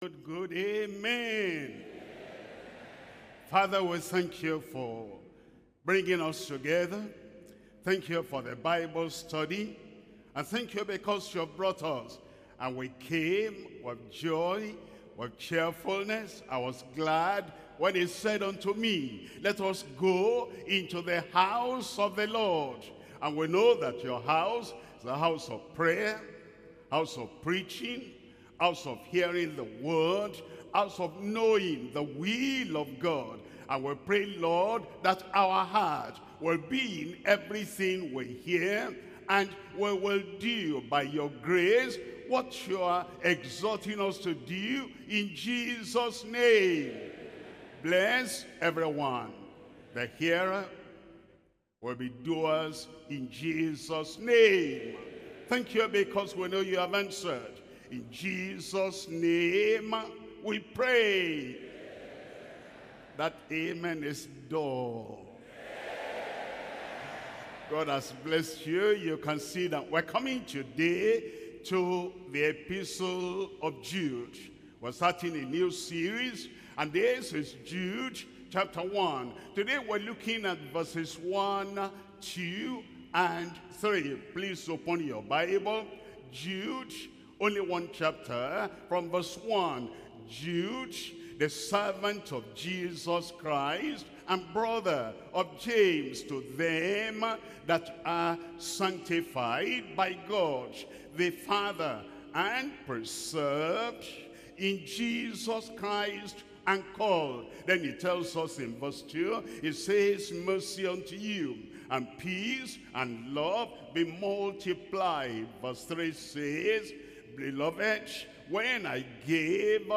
Sermons - Deeper Christian Life Ministry
Pastor W.F. Kumuyi
Bible Study